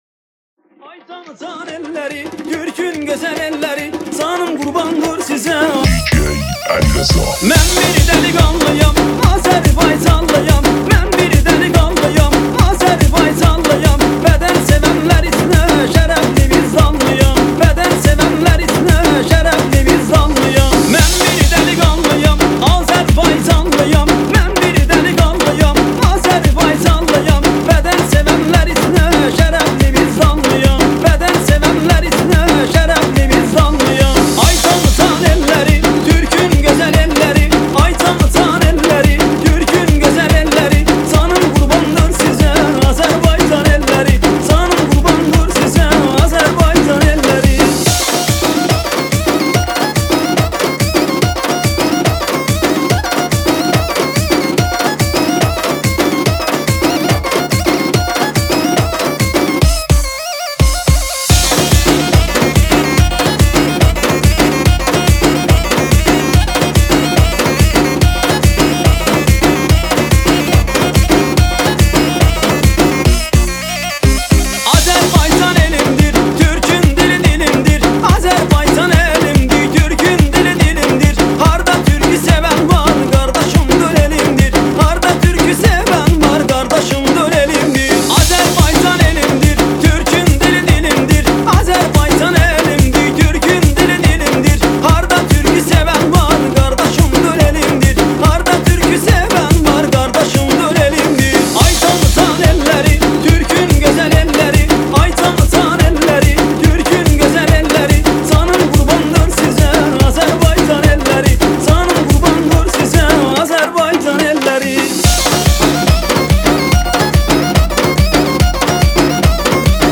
ریمیکس شاد ترکی